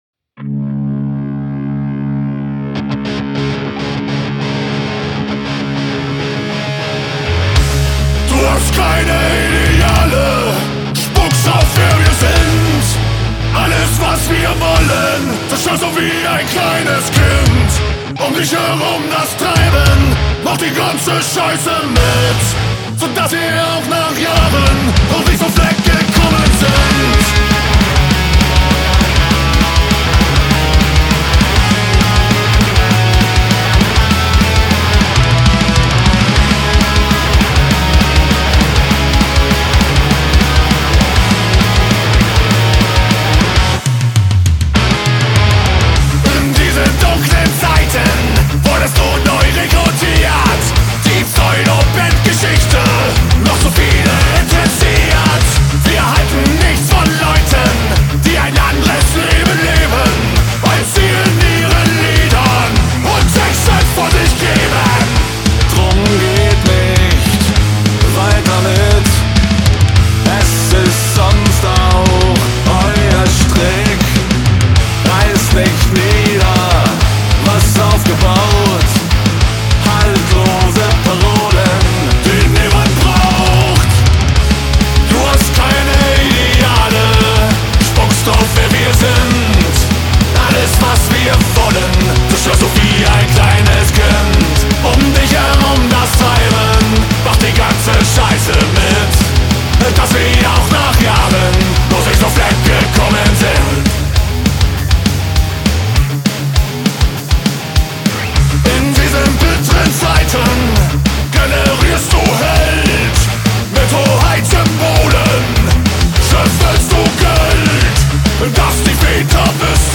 Musikalisch abwechslungsreich und experimentell komponiert